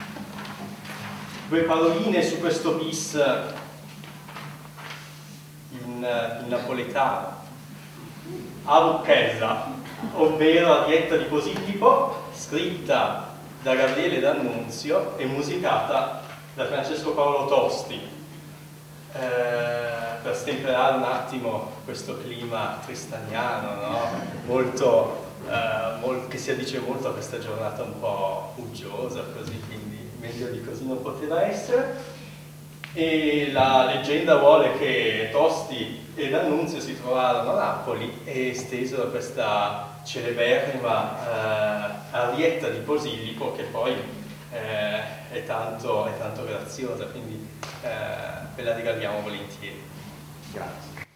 III OTTOBRE MUSICALE A PALAZZO VALPERGA
Introduzione